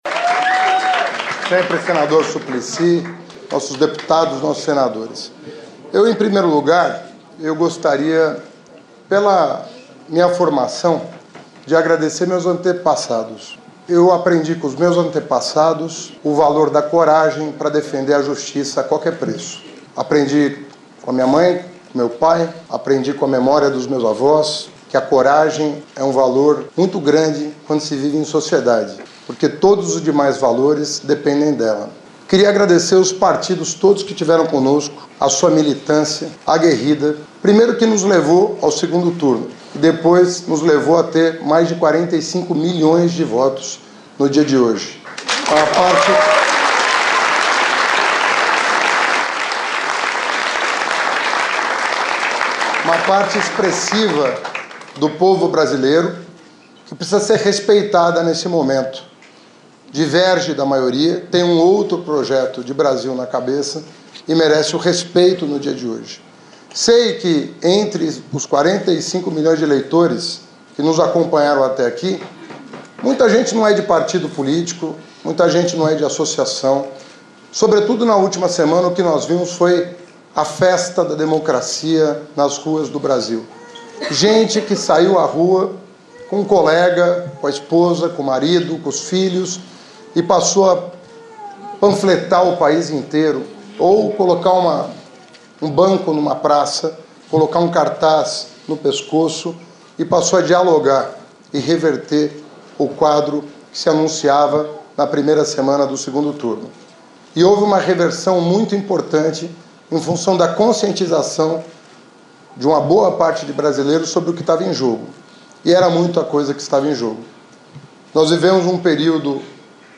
Pronunciamento de Fernando Haddad
O candidato do PT à Presidência da República, Fernando Haddad, fez um pronunciamento neste domingo (28) logo após a definição do resultado das eleições.